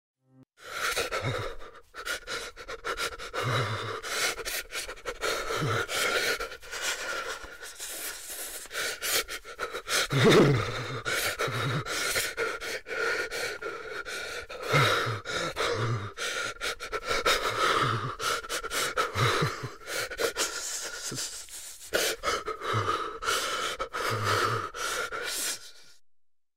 Âm thanh Run Rẩy (âm thanh thực)
Thể loại: Tiếng con người
Description: Âm thanh Run Rẩy (âm thanh thực), tiếng rung, rung lắc, run run, chập chờn, rung giật... SFX này tái hiện cảm giác bất ổn, hồi hộp, sợ hãi, hơi thở run... Khi edit video...
am-thanh-run-ray-am-thanh-thuc-www_tiengdong_com.mp3